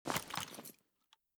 ak74_holster.ogg.bak